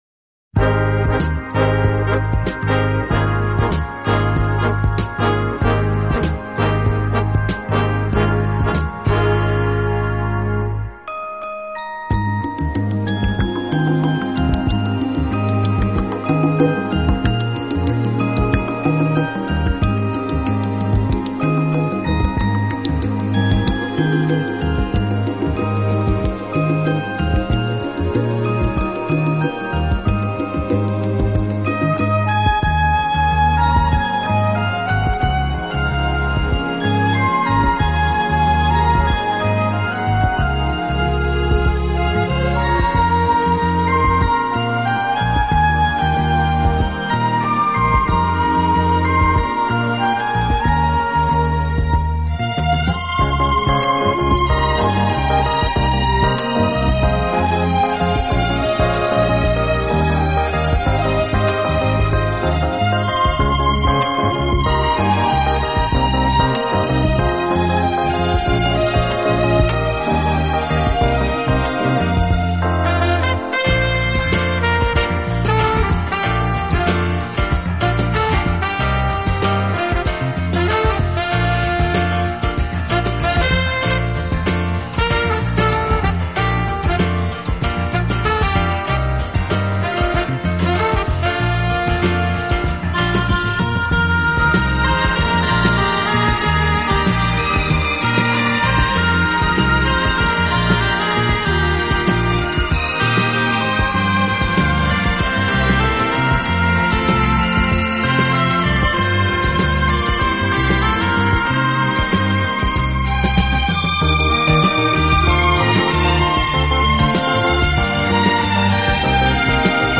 【顶级轻音乐】
好处的管乐组合，给人以美不胜收之感。